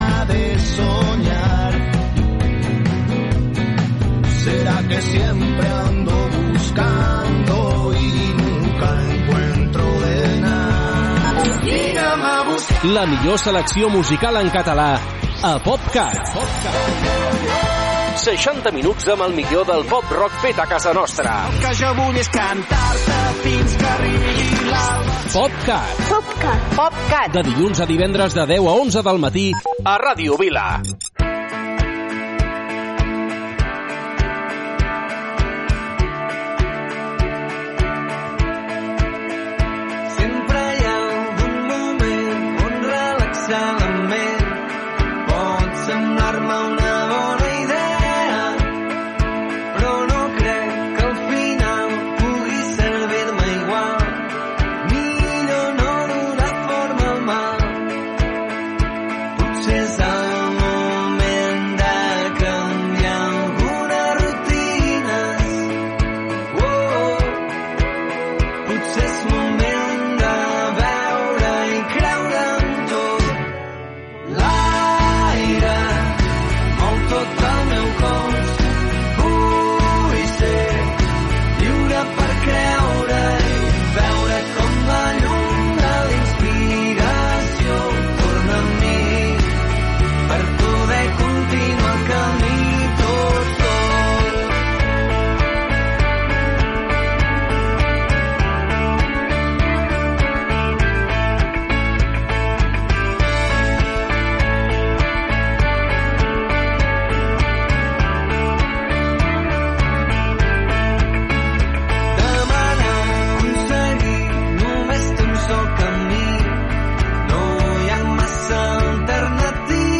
POP Cat. 60 minuts de la millor música feta a casa nostra.